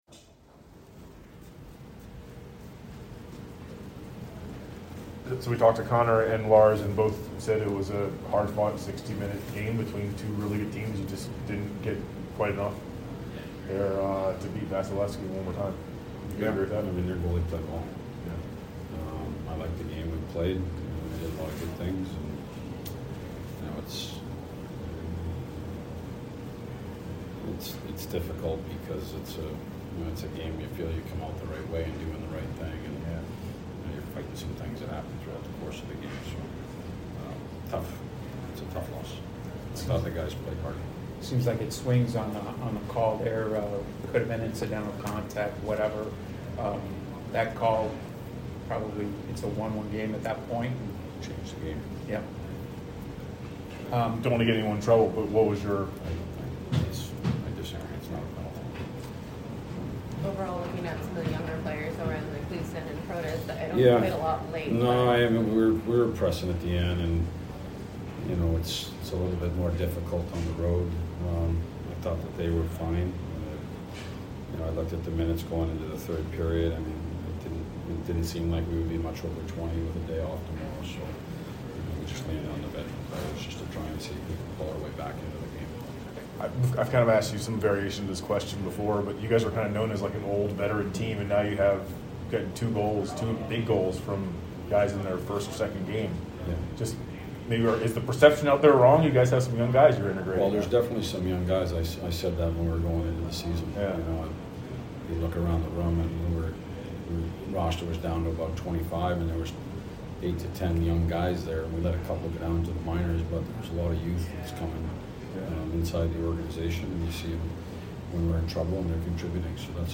Head Coach Peter Laviolette Post Game 11/1/21